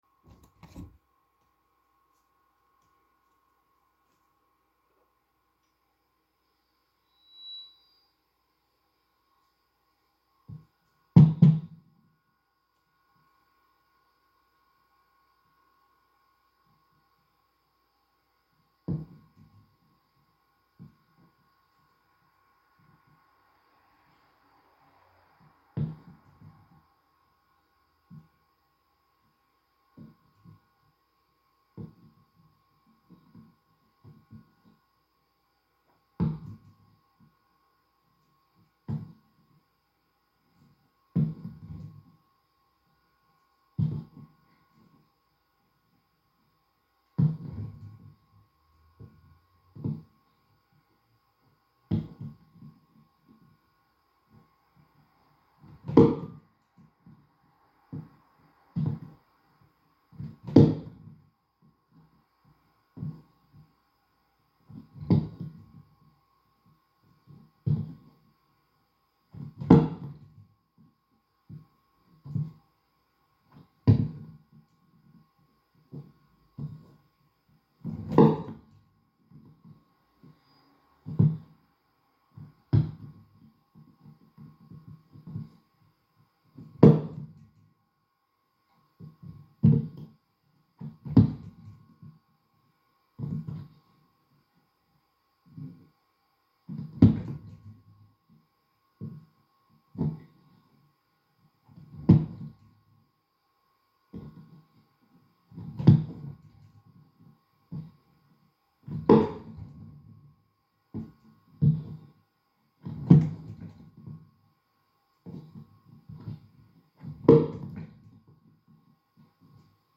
Microfono, impianto di amplificazione, cassa, corpo, impatti.
Microphone, amplification system, speaker, body, impacts. Performance, 2022.